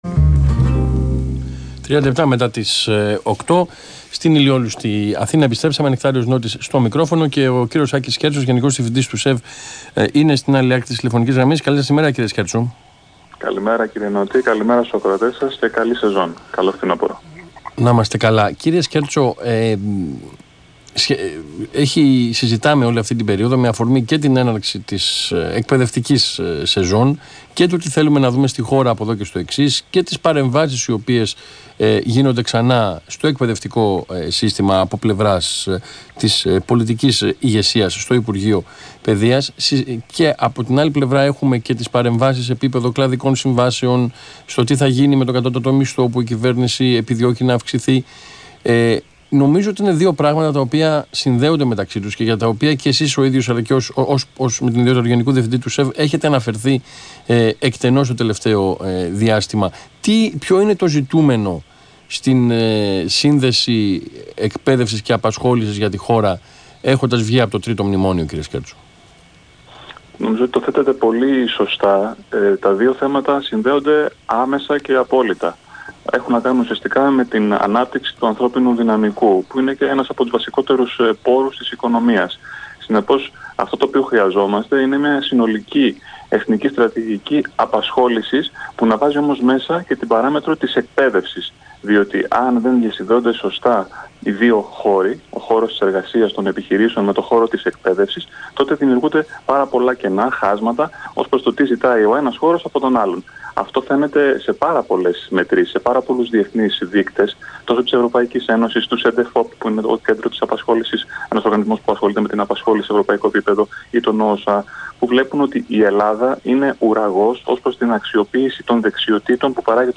Συνέντευξη του Γενικού Διευθυντή του ΣΕΒ, κ. Άκη Σκέρτσου στον Ρ/Σ ΑΘΗΝΑ 9.84, 5/9/2018